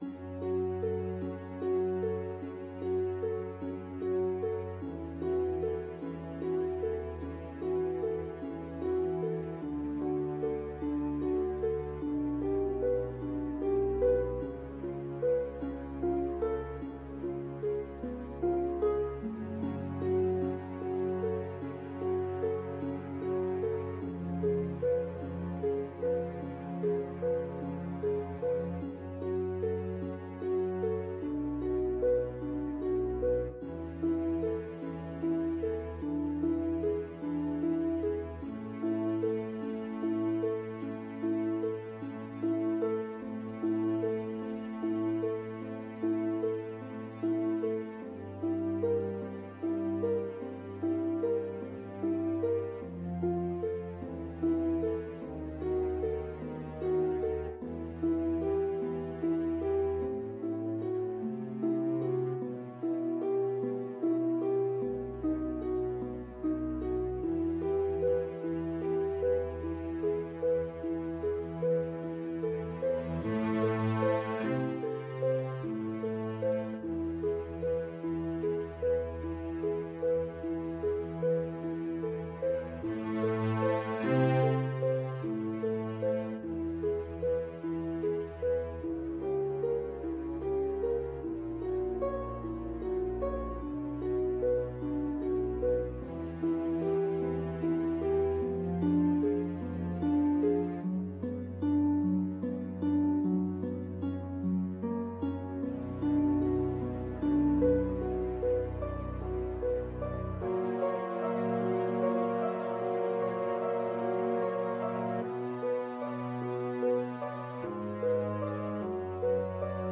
ViolaPiano
Largo
Viola  (View more Easy Viola Music)
Classical (View more Classical Viola Music)